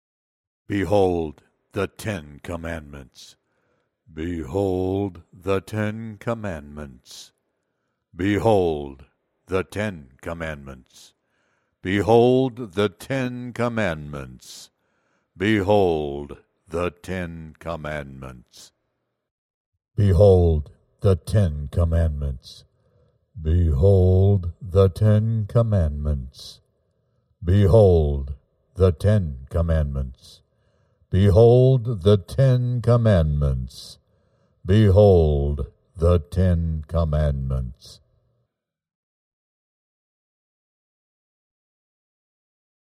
配音要求 " 十条诫律